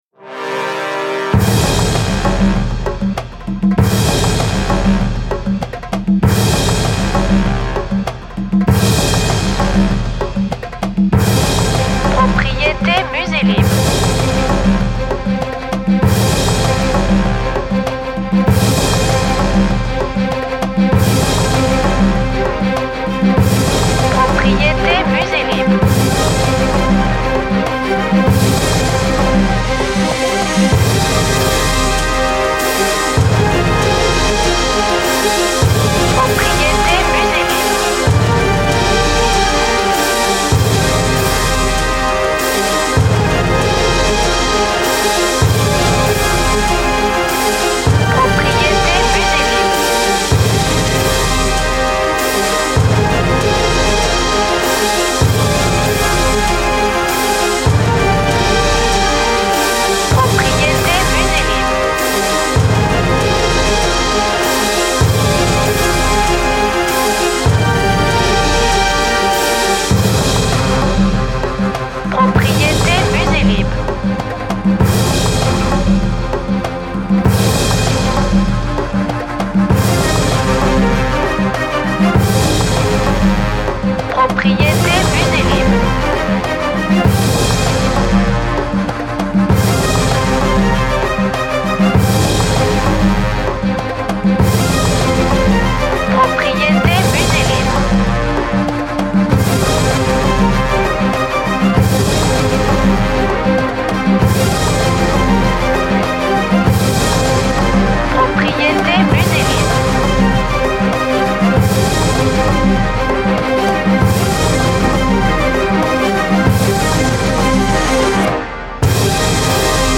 BPM Fast